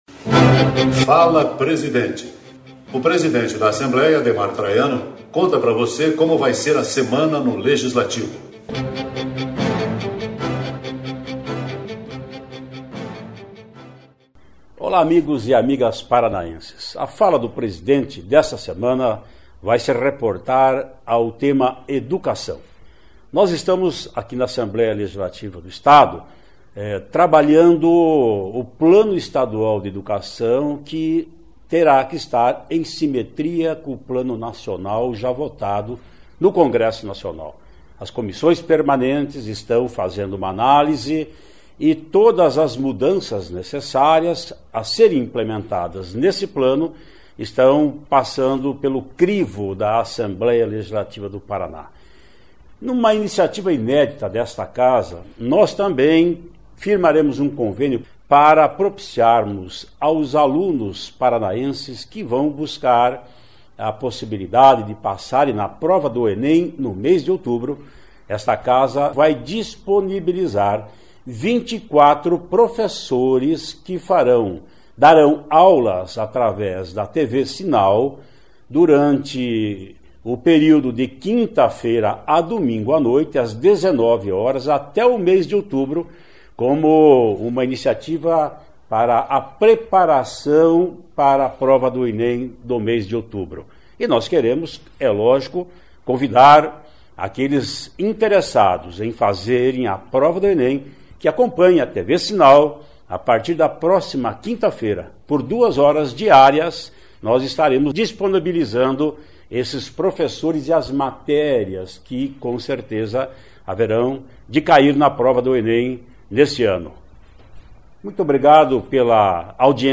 Presidente Ademar Traiano adianta pra você o que vai ser notícia no Legislativo esta semana.